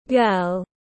Bé gái tiếng anh gọi là girl, phiên âm tiếng anh đọc là /ɡɜːl/.
Girl.mp3